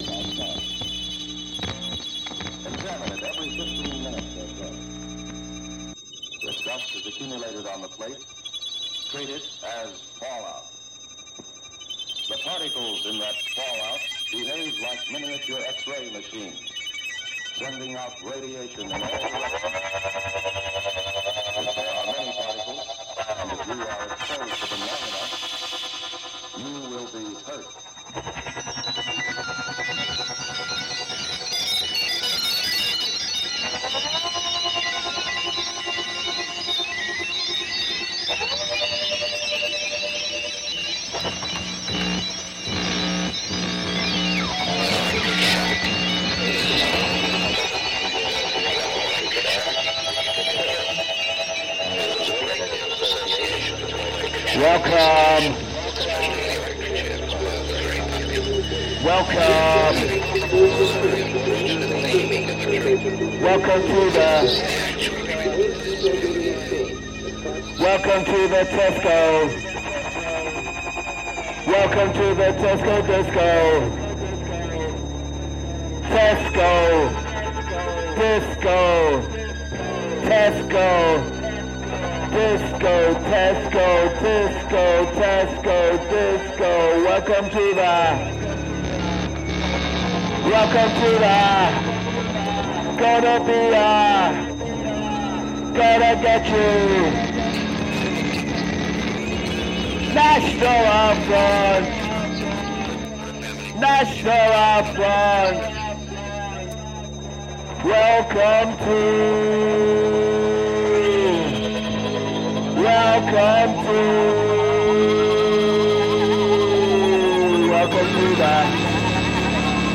achieves levels of coruscating atonality
• Genre: Industrial / Experimental